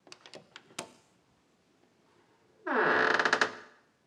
SFX_Door_Open_03.wav